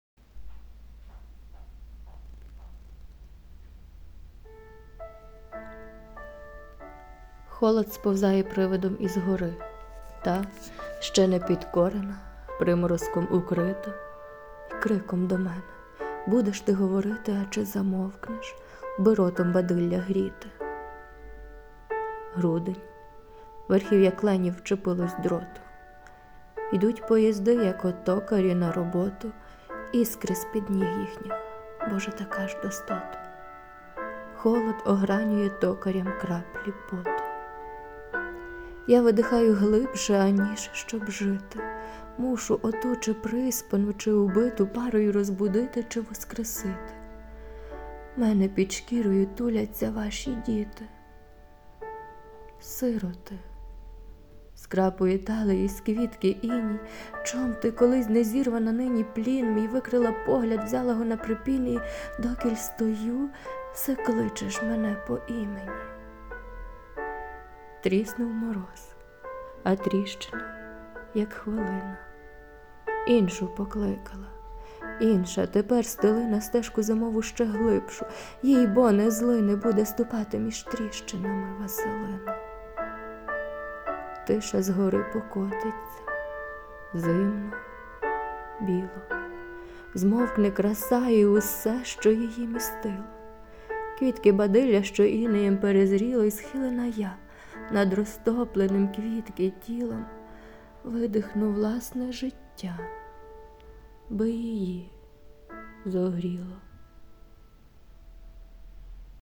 ВИД ТВОРУ: Вірш
В мене мороз по шкірі від Вашого твору у Вашому виконанні.